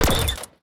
UIClick_Menu Strong Metal Rustle 01.wav